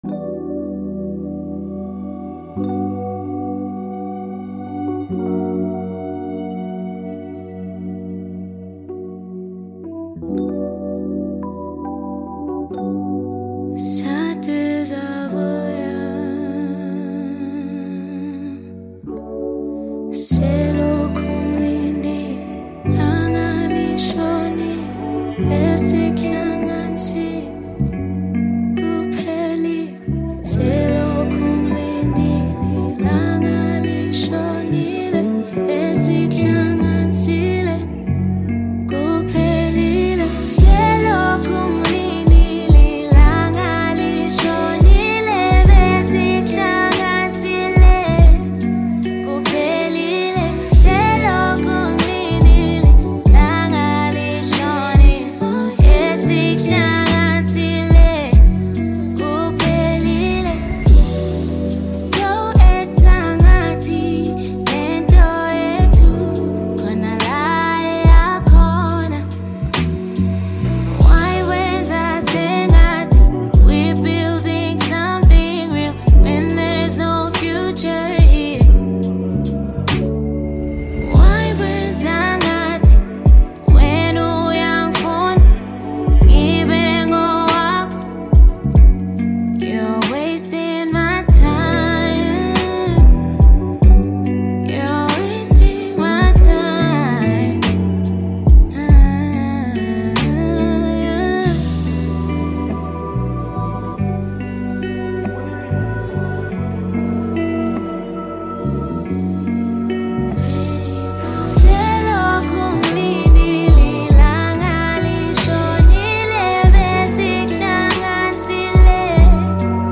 strong vocals